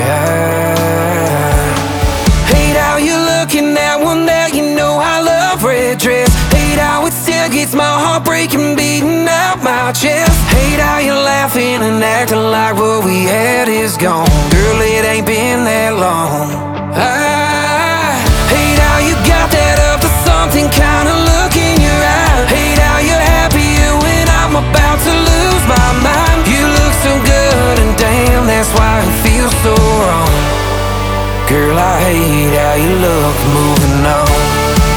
Жанр: Кантри